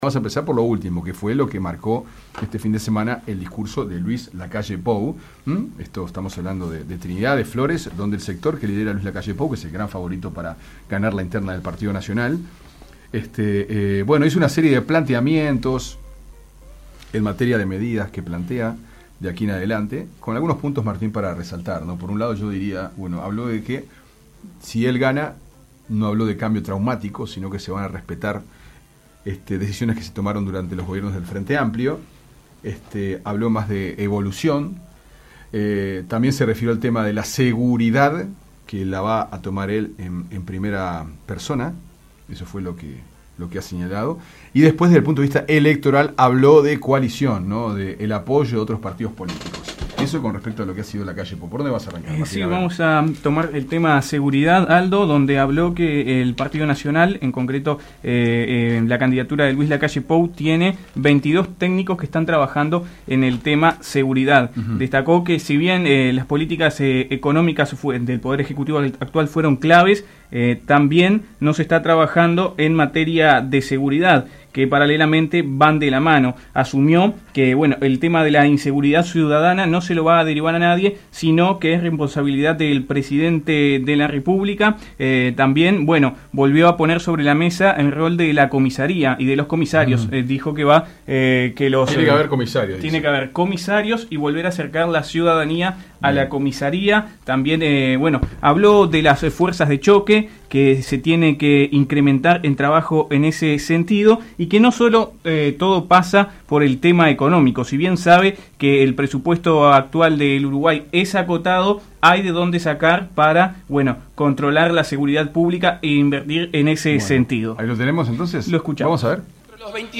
«El cambio en sí mismo no tiene una connotación positiva. No necesariamente es bueno. Simplemente establece que yo quiero cambiar, lo que no quiere decir es que ese cambio sea para bien. Evolucionar sí tiene una connotación positiva», explicó el precandidato en el discurso de cierre del congreso de «Todos» en Trinidad.